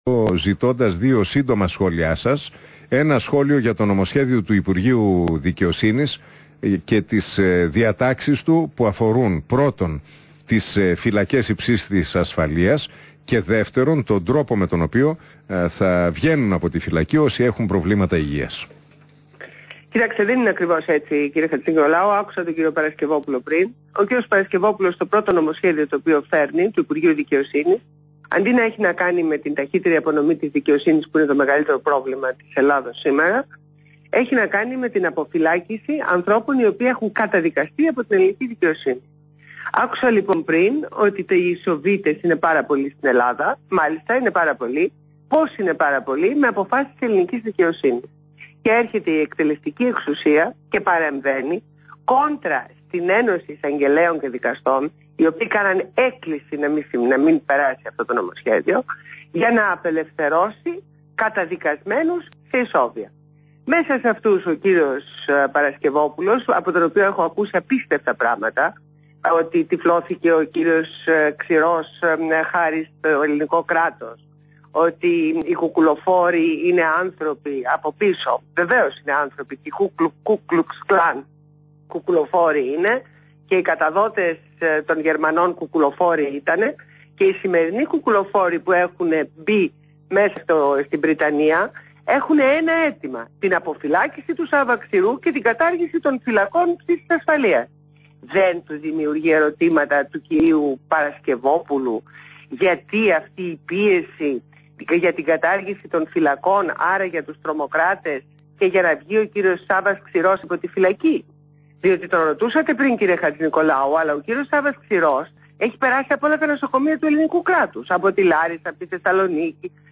Συνέντευξη στο ραδιόφωνο του REALfm στον Ν. Χατζηνικολάου.